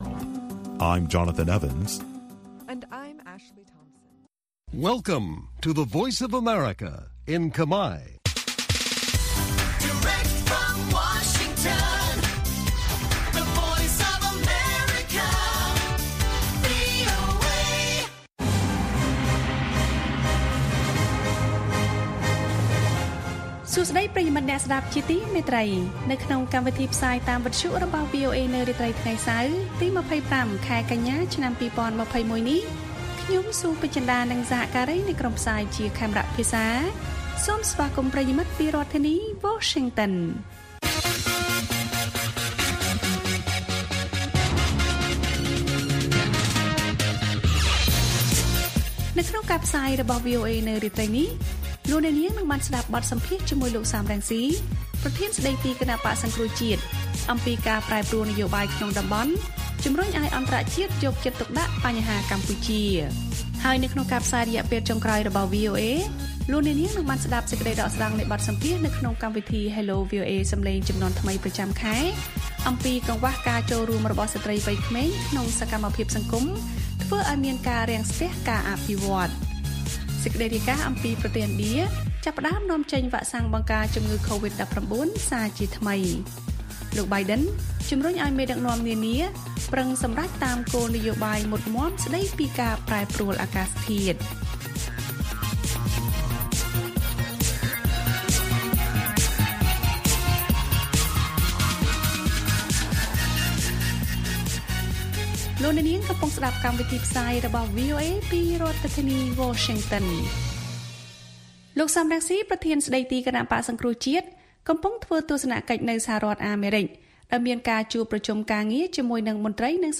ព័ត៌មានអំពីកម្ពុជា បទសម្ភាសន៍ VOA៖ លោក សម រង្ស៊ី ជឿជាក់ថា ការប្រែប្រួលនយោបាយក្នុងតំបន់ជំរុញឲ្យអន្តរជាតិយកចិត្តទុកដាក់បញ្ហាកម្ពុជា។